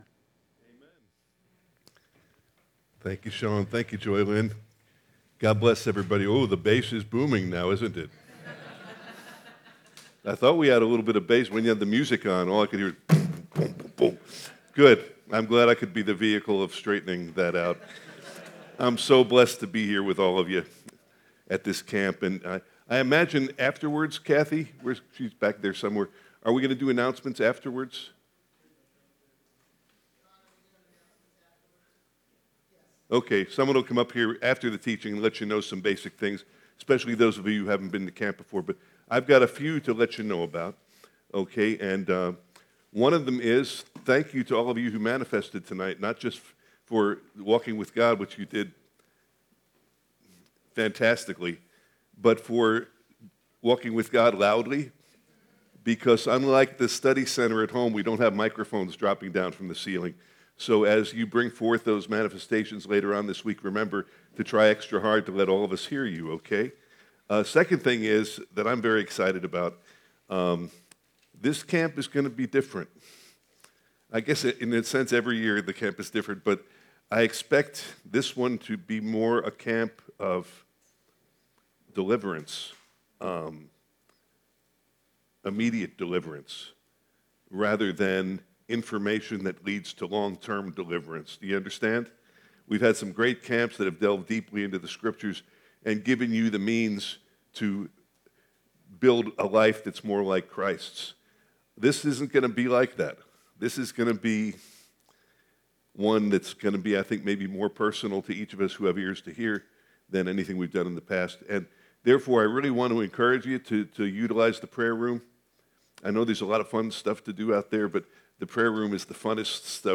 An introductory teaching for a verse-by-verse teaching series on 1 and 2 Thessalonians with an emphasis on how our hope helps us to live holy lives until Christ returns.
Introduction Our Daily Hope (Family Camp 2024